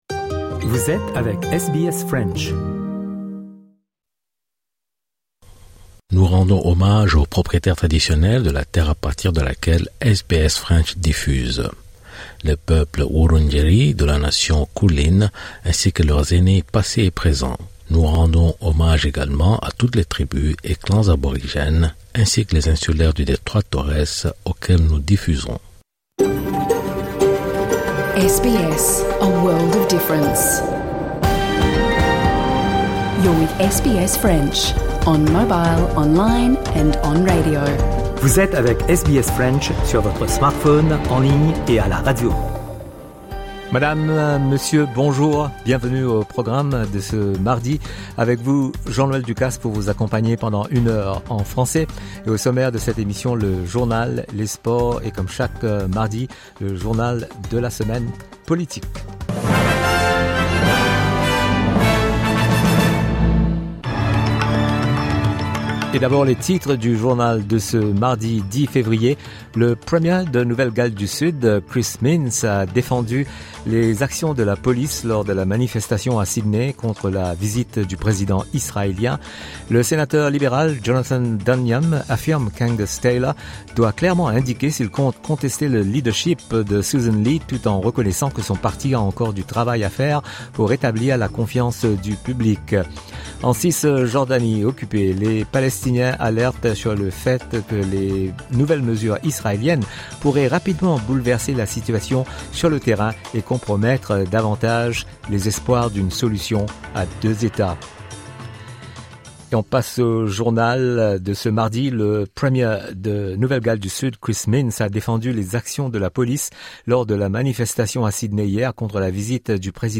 Retrouvez l'émission du mardi 10 février 2026 en (presque) intégralité.